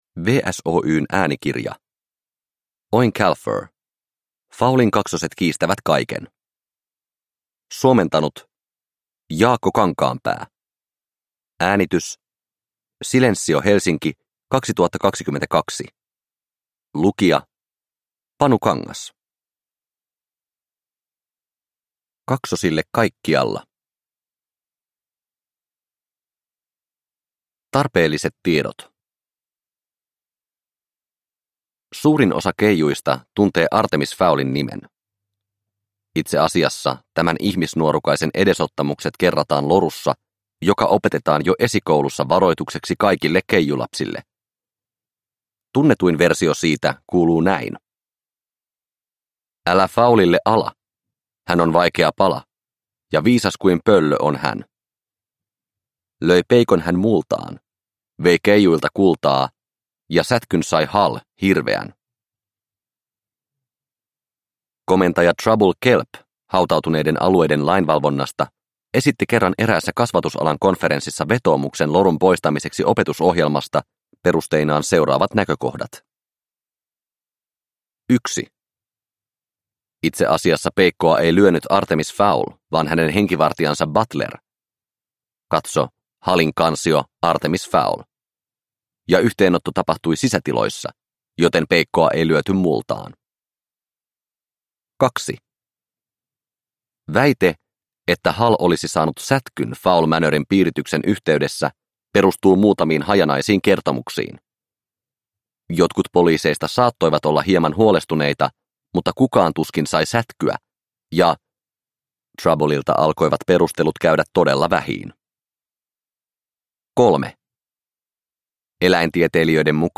Fowlin kaksoset kiistävät kaiken – Ljudbok – Laddas ner